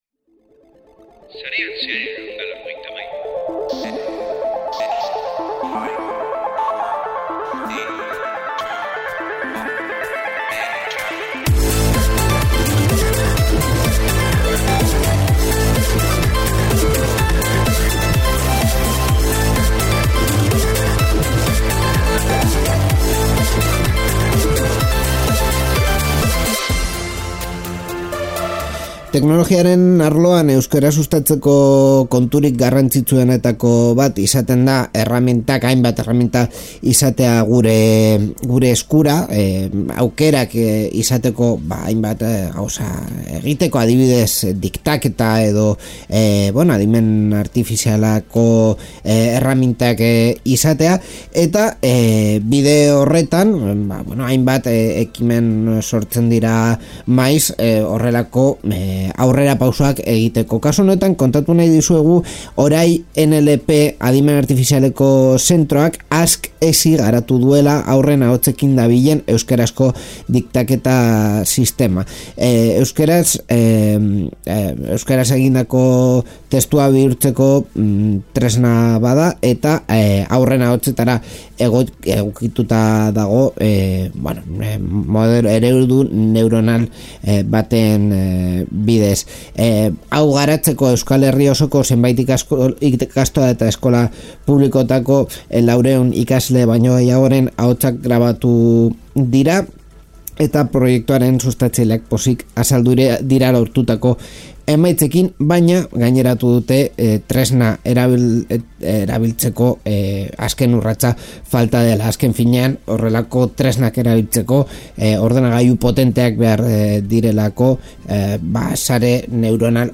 Sarean Zehar irratsaio teknologikoa da. Ordu batean berri garrantsitzuen birpasoa egiten dugu, guneko ekitakdi interesgarriak bilatzen ditugu eta ekimen teknologiko interesgarrienak ezagutzen ditugu.